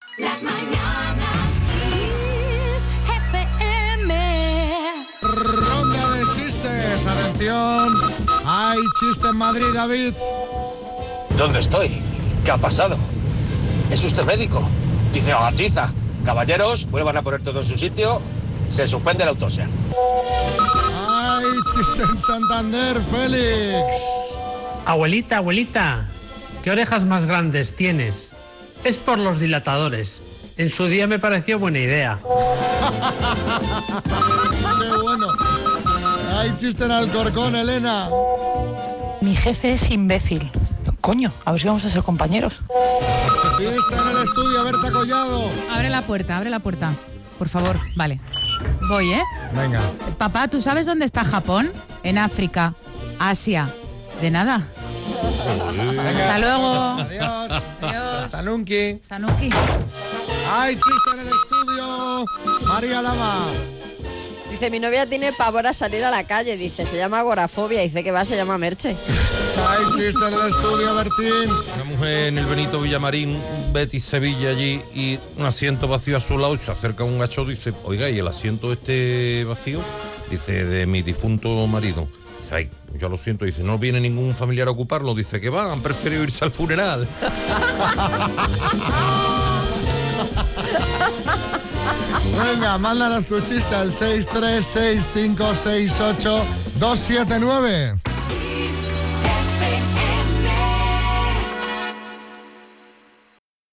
Recopilamos los mejores chistes de nuestros oyentes.